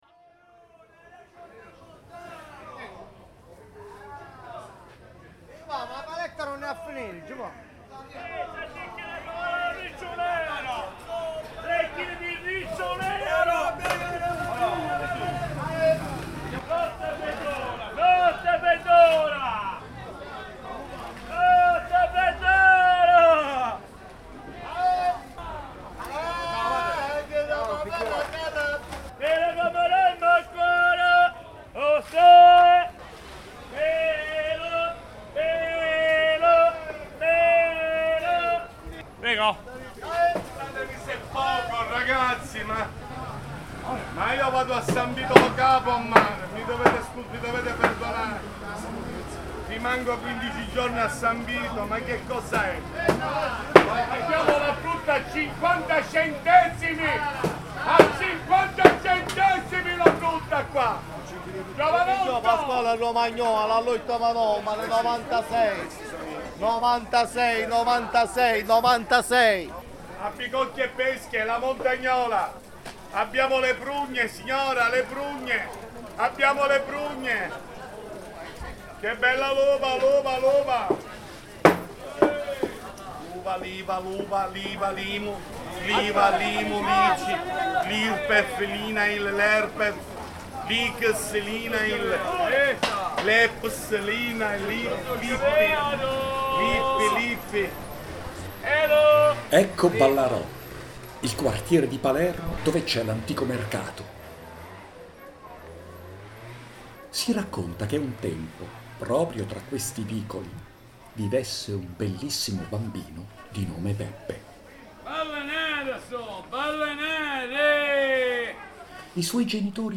In questo audio si gioca con i suoni del mercato, parte della storia narrata, gli abitanti di Ballarò.